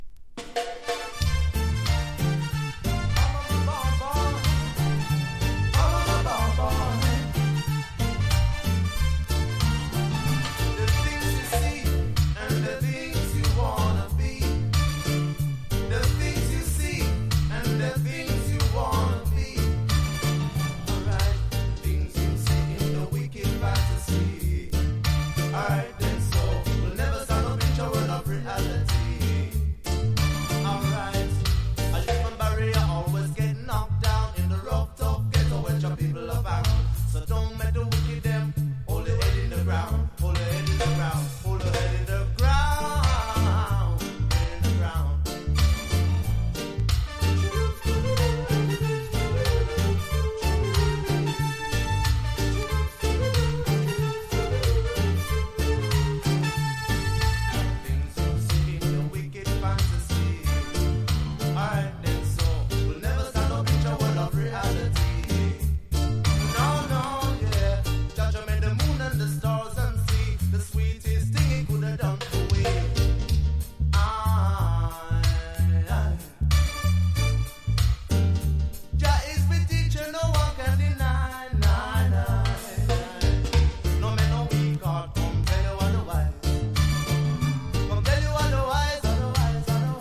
• REGGAE-SKA
DANCE HALL